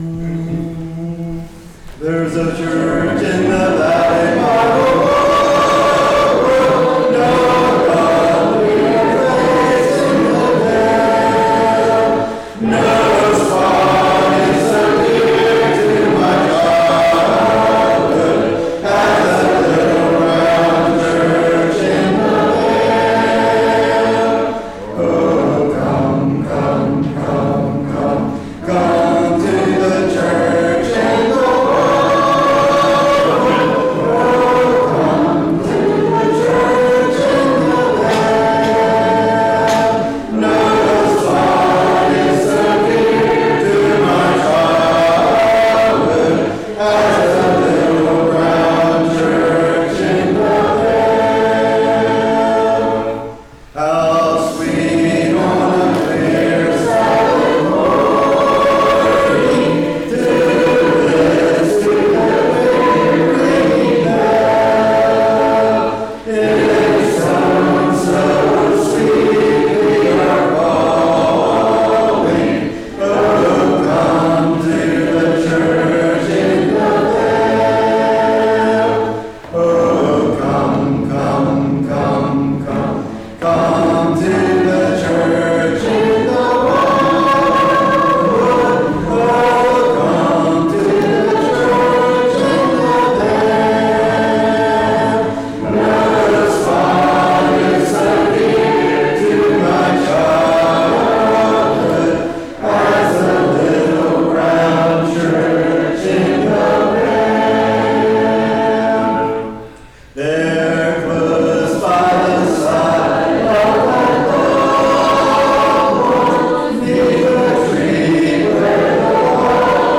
Hymn Sing